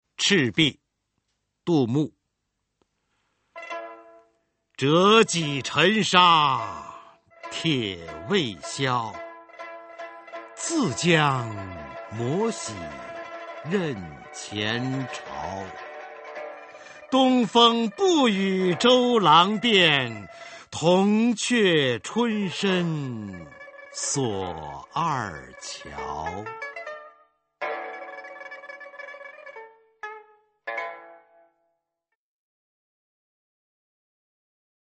[隋唐诗词诵读]杜牧-赤壁（男） 古诗文诵读